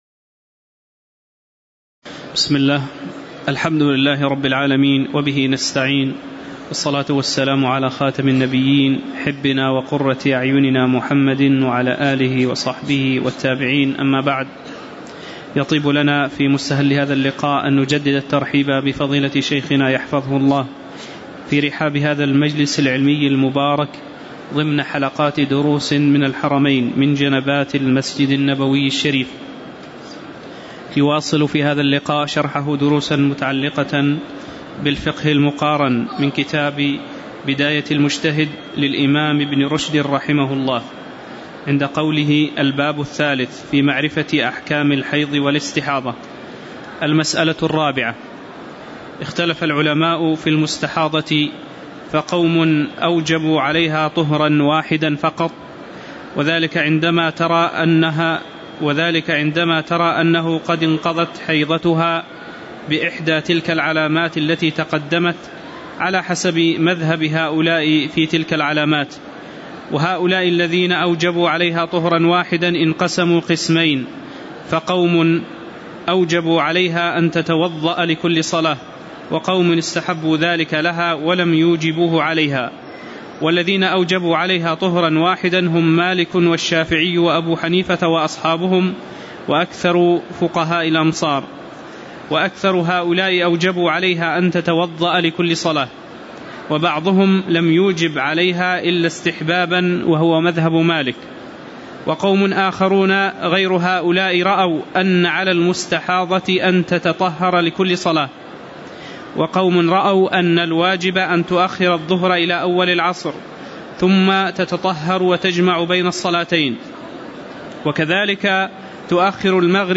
تاريخ النشر ٥ جمادى الآخرة ١٤٤٠ هـ المكان: المسجد النبوي الشيخ